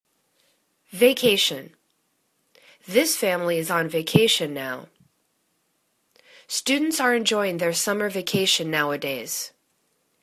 va.ca.tion     /və'ka:shən/    n